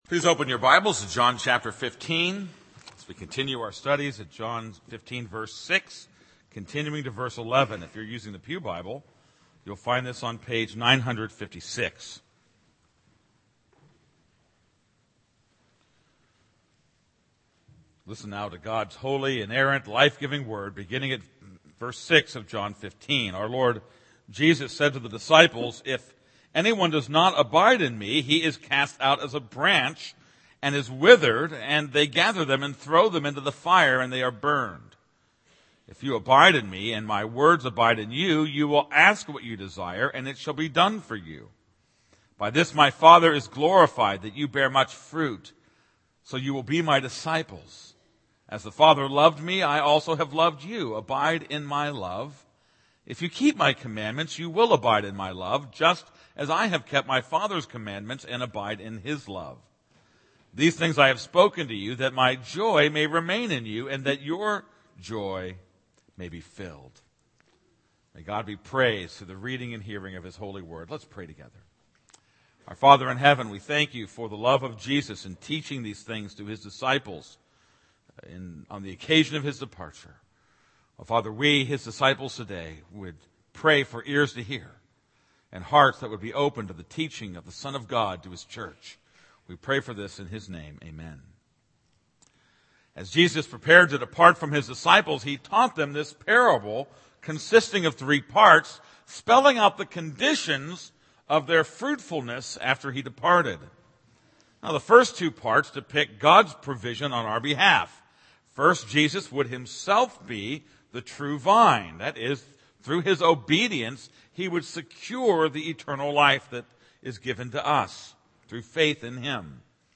This is a sermon on John 15:6-11.